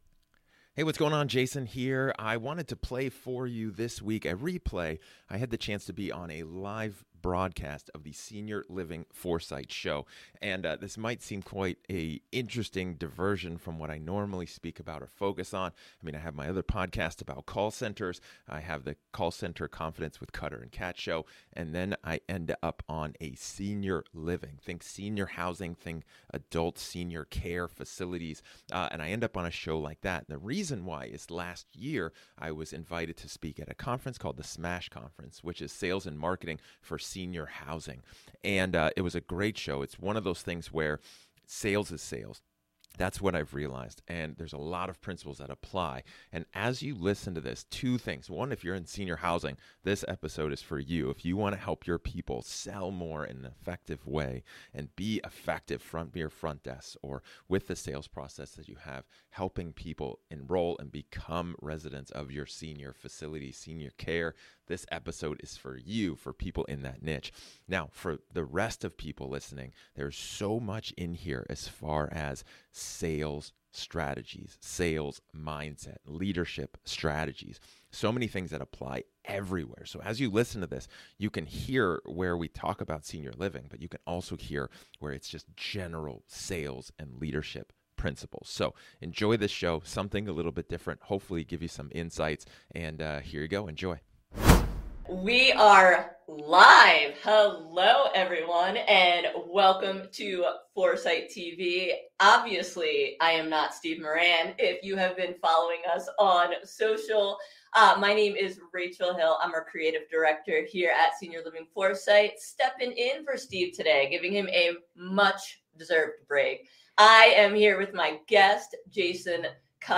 [E422] Conversation on the Senior Living Foresight Show